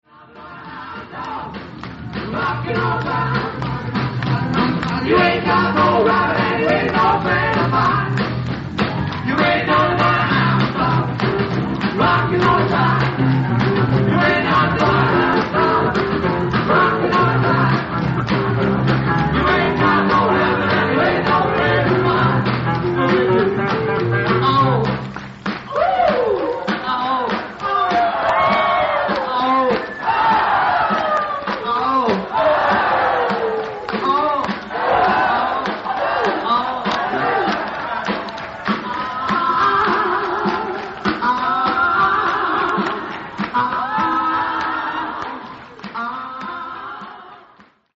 Venue:  Nagoyashii Kokaido
Source:  Audience Recording